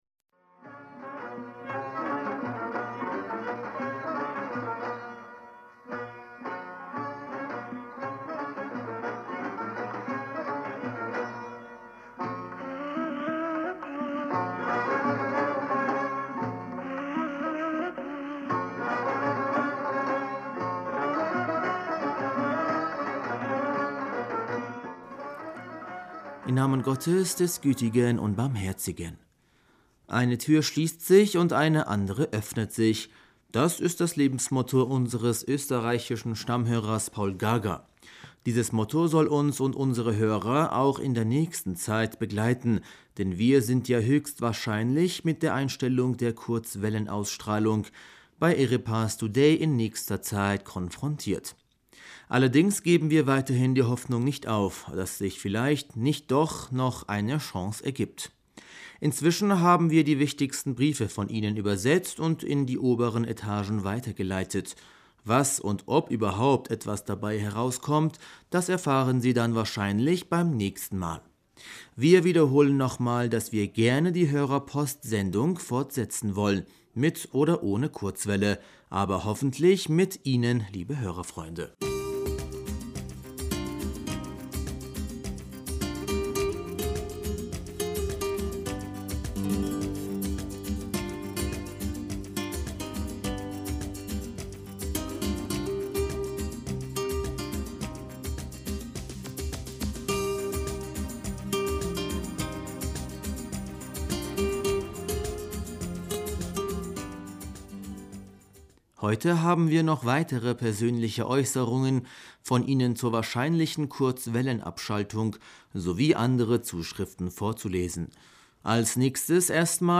Hörerpostsendung am 15. Dezember 2019 Bismillaher rahmaner rahim -